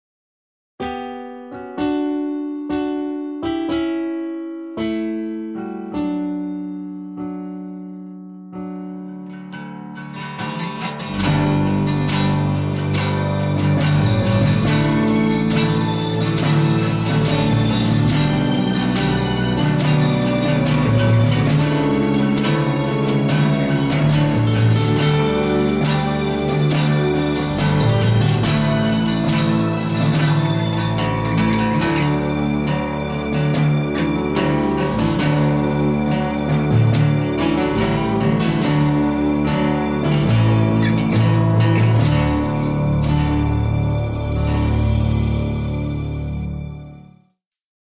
Orchestral Scores
Well, with my keyboard, I can finally start to work on rough orchestral pieces.
I posted a rough draft of a theme I composed for a wedding video my side business is working on.